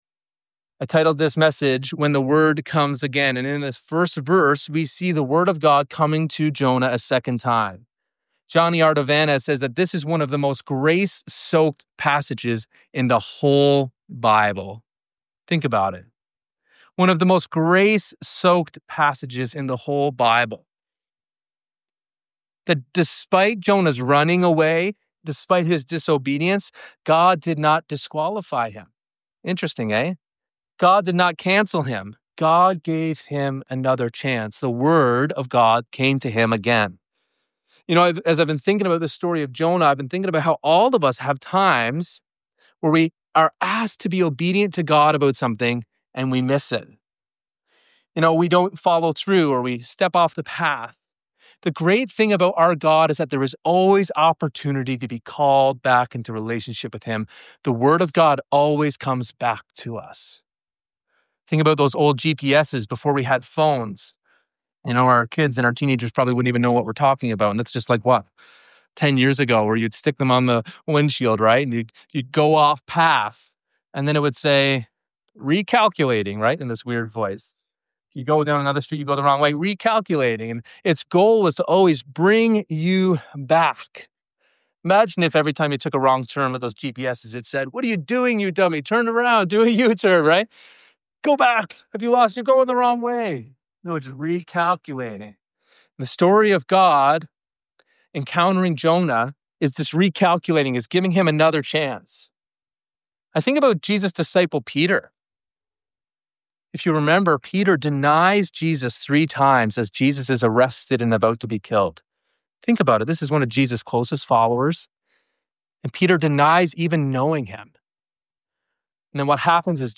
Just as God gave Jonah a second chance, He continually calls us to recalculate and return to His path in obedience. The sermon unpacks how God’s Word both confronts sin and transforms hearts—turning rebellion into revival.